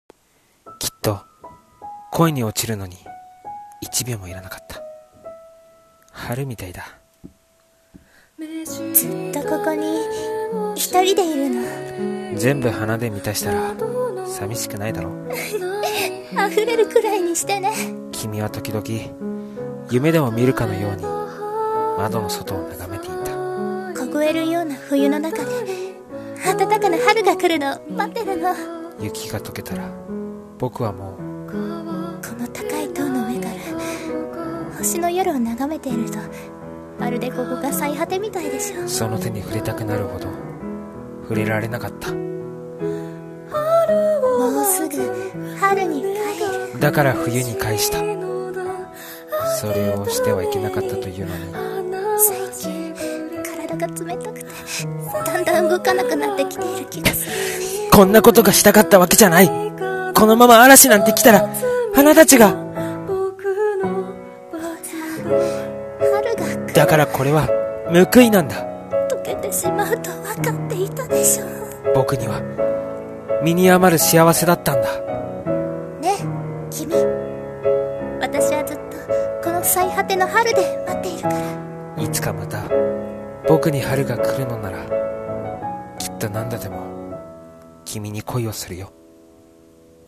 【予告風声劇】